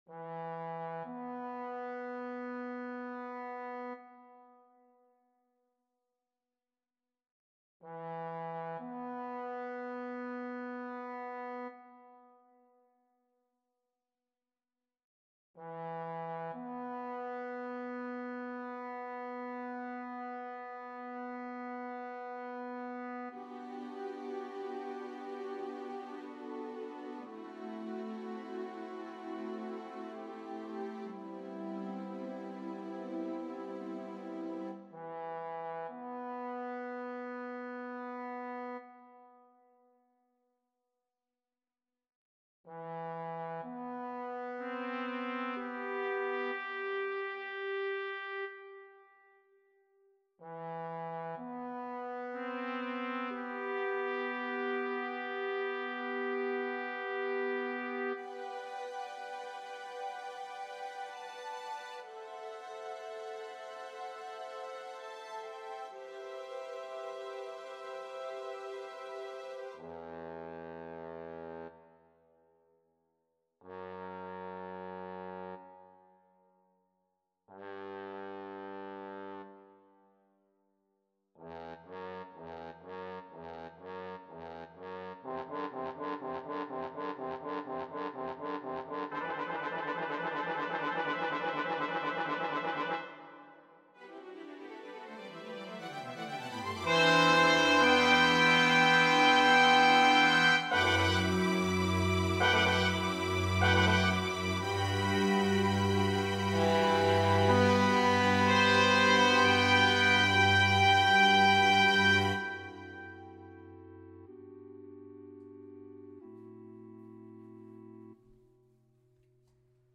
Inte döda draken (gosskören)